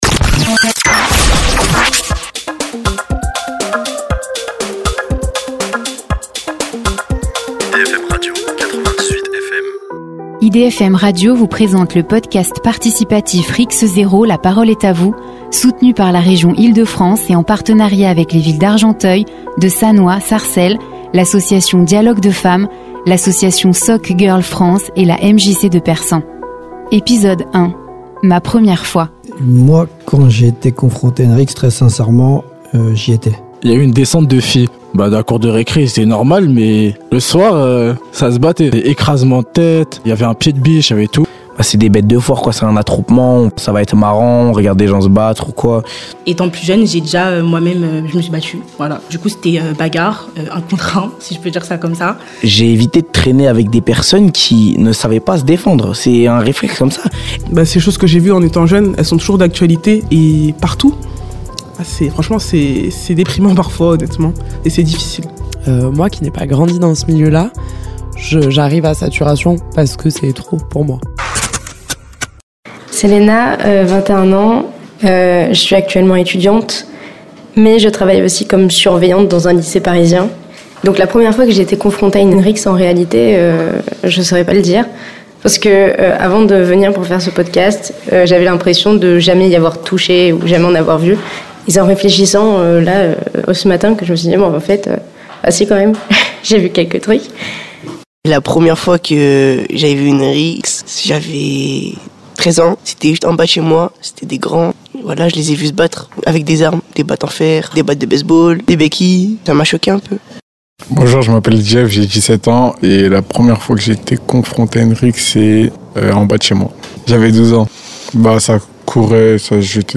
Élus, Police Municipale, associations et acteurs de terrain prennent la parole pour témoigner.